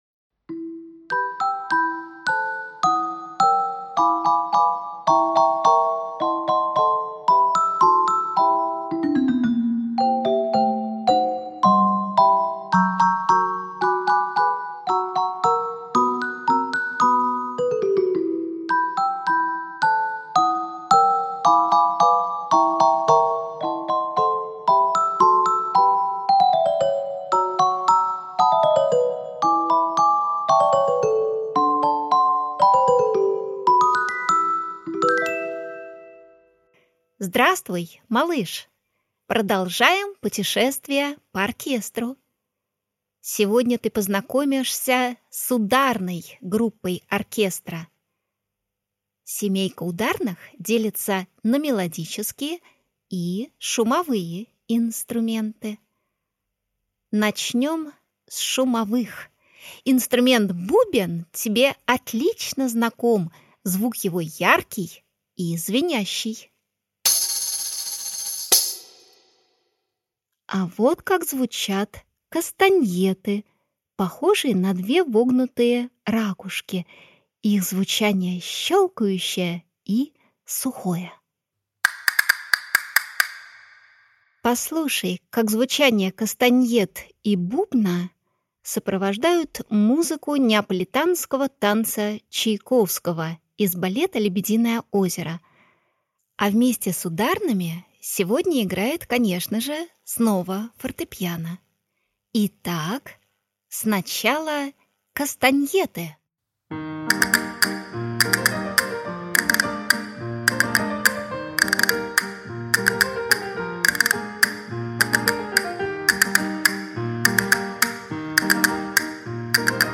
Аудиокнига Семейка ударных. Путешествуем с Щелкунчиком, Горным королём, лунным котёнком и Крошкой Енотом | Библиотека аудиокниг